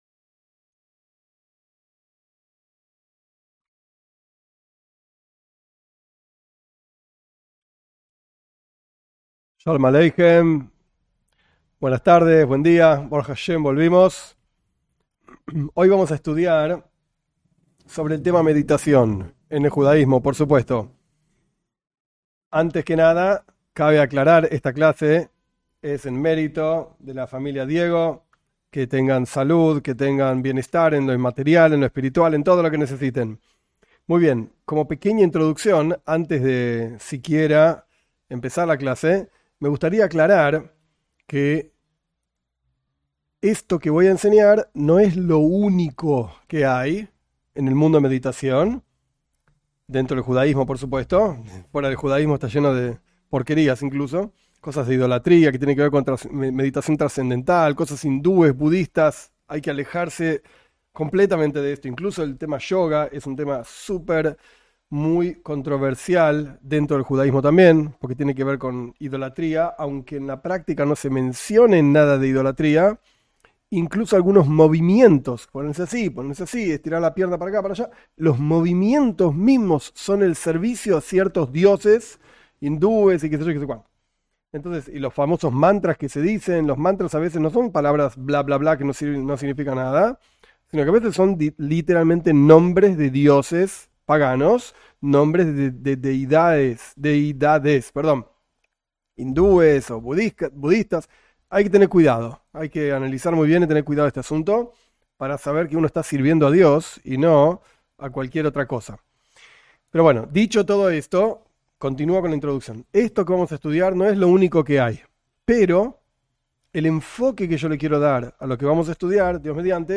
En esta clase se explica el concepto de meditación. Su importancia y los diferentes tipos de meditación.